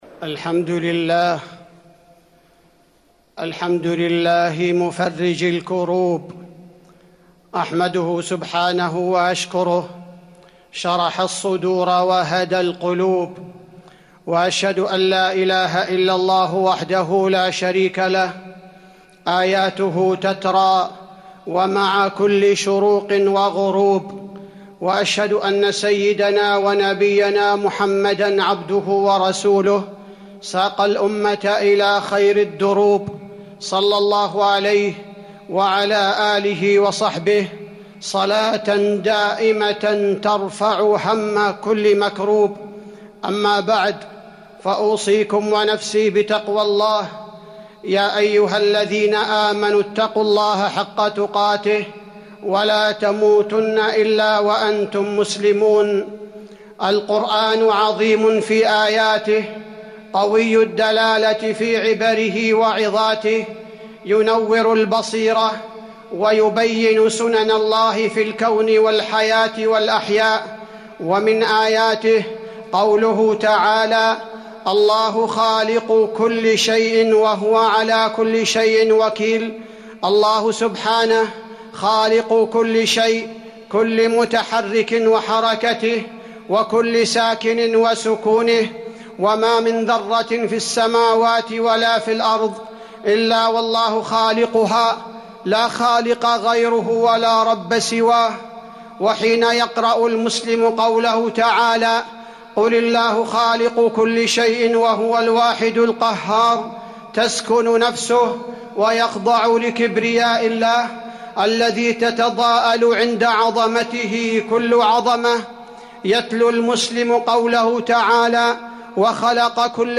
تاريخ النشر ١٠ شعبان ١٤٤١ هـ المكان: المسجد النبوي الشيخ: فضيلة الشيخ عبدالباري الثبيتي فضيلة الشيخ عبدالباري الثبيتي سنة الله في الخلق